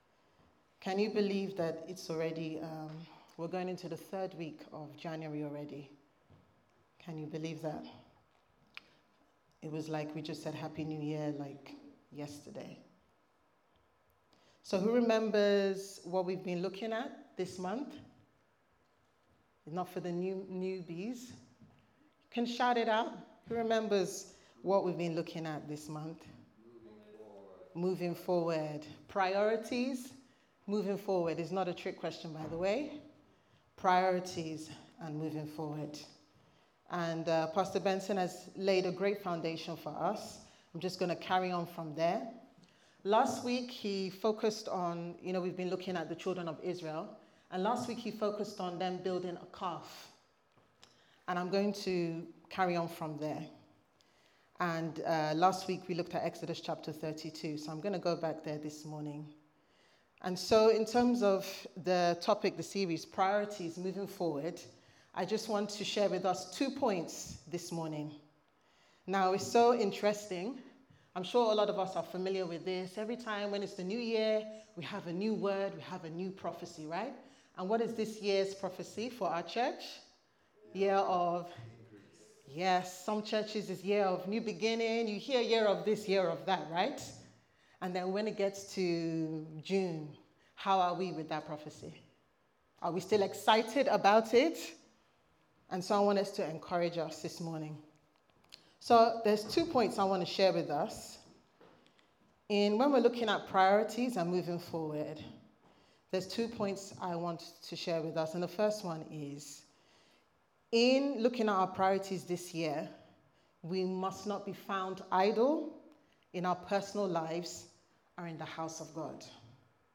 Priorities Service Type: Sunday Service Sermon « Moving Forward